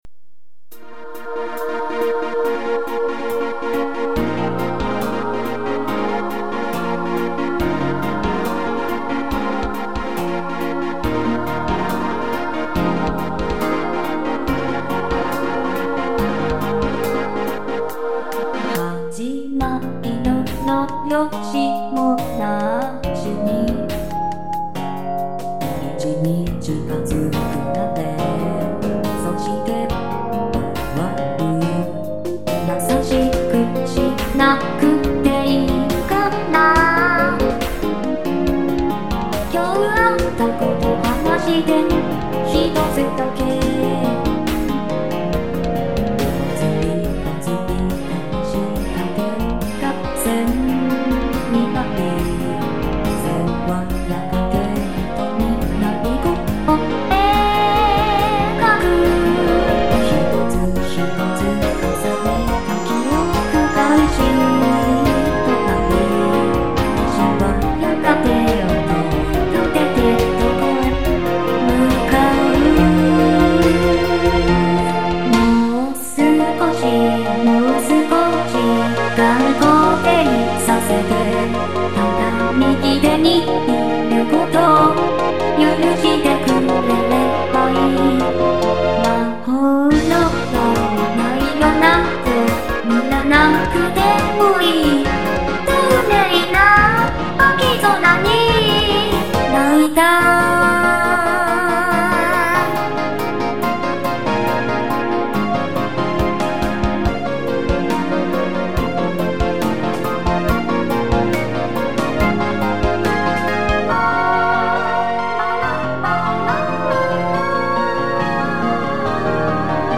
調教は今までの中で一番安定している…気がする、しかし今度は曲が残念なことに。（サビがサビらしくないというか…メリハリ皆無）とりあえず勿体無いので1番のみサンプル公開しますが、今後も完成させるかどうかはわかりません。